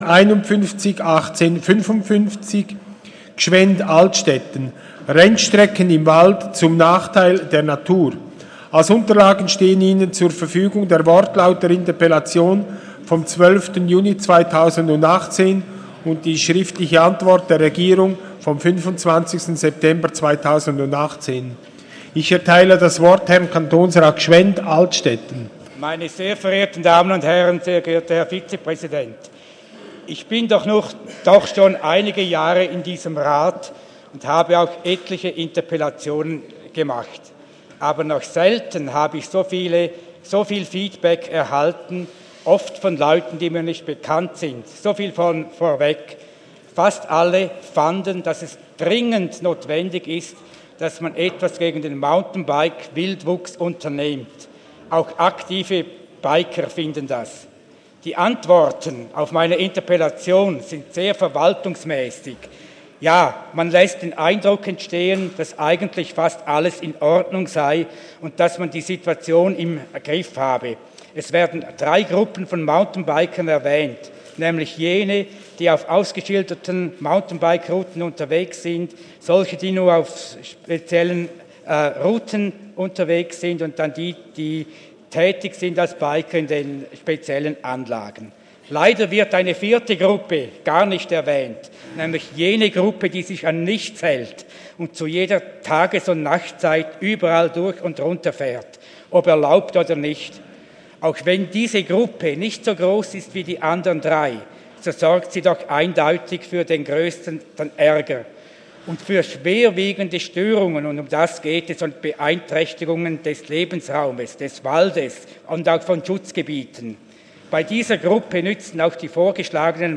27.11.2018Wortmeldung
Session des Kantonsrates vom 26. bis 28. November 2018